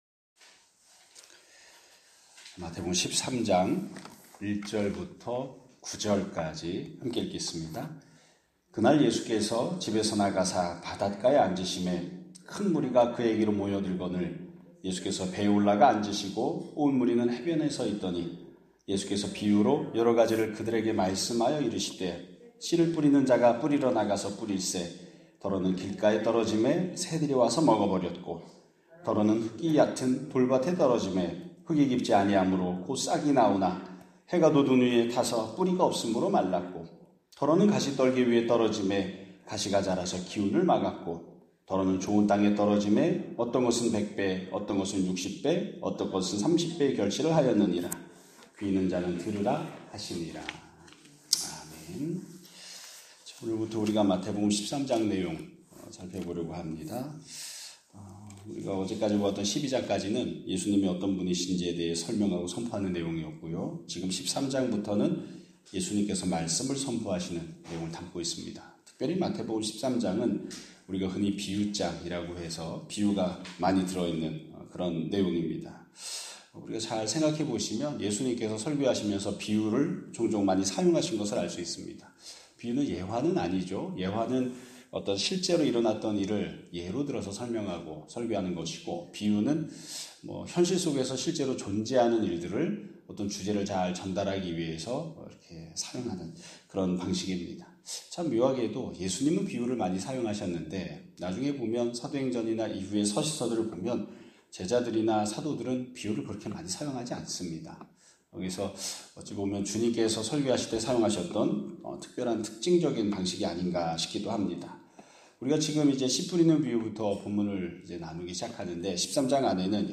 2025년 9월 24일 (수요일) <아침예배> 설교입니다.